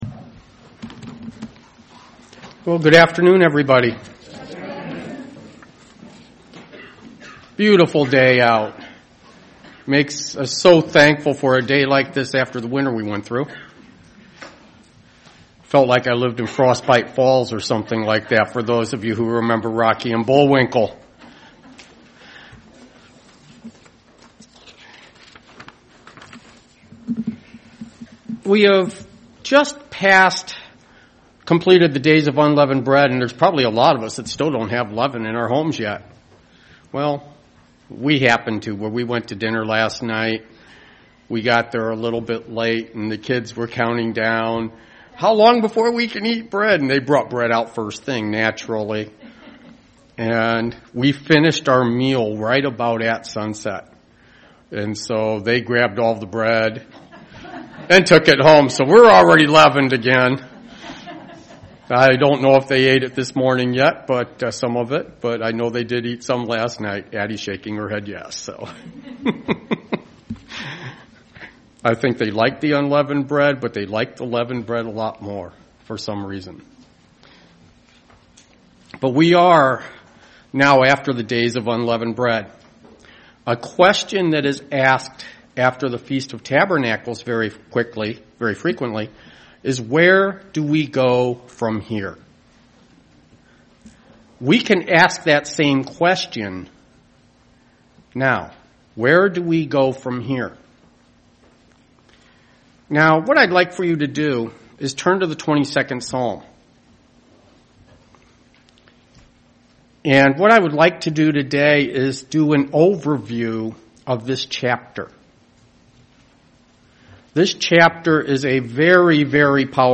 UCG Sermon Studying the bible?
Given in Dayton, OH